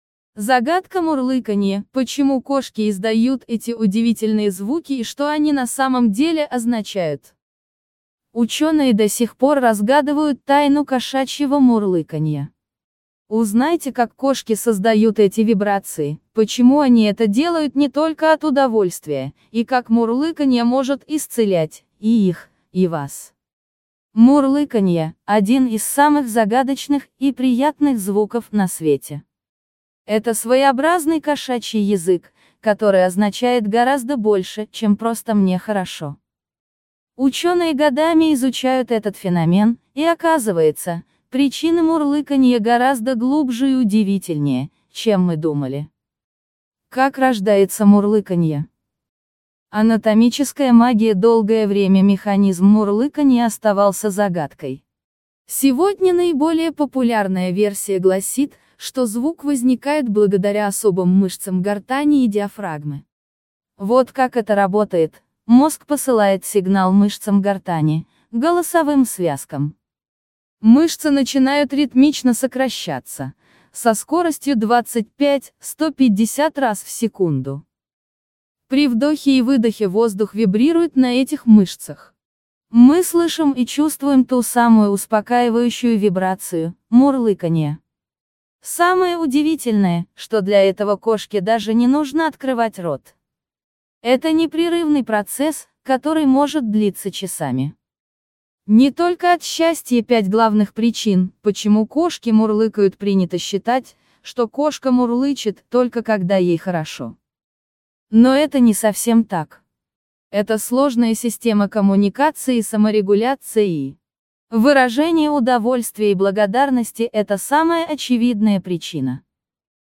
Жанр: Подкаст